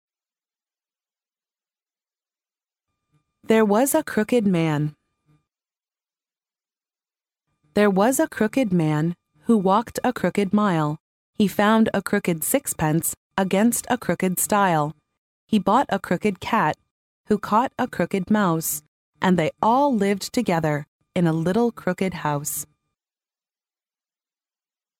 幼儿英语童谣朗读 第11期:有一个罗锅老头 听力文件下载—在线英语听力室